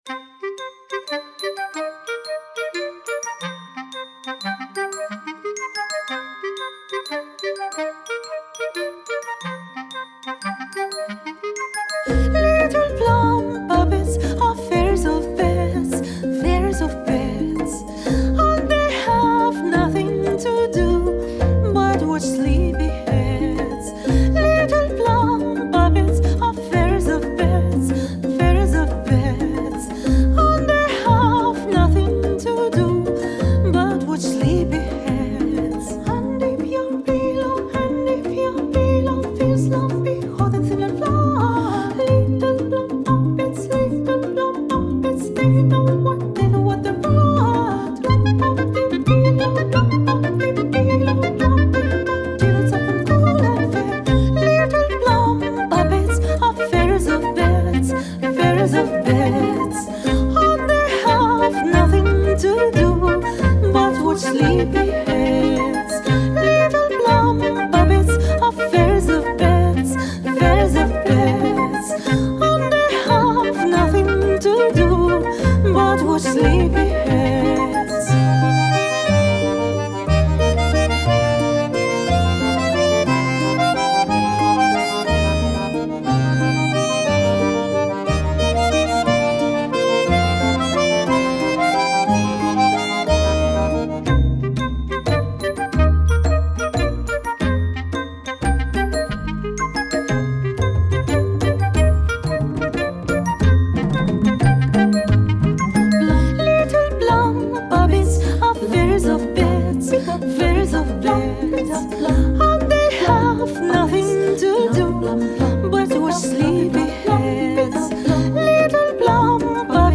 妖精が舞うファンタジー溢れる世界観は相変わらず絶品。
voice
keyboards
flute
clarinet
bassoon
violin
harp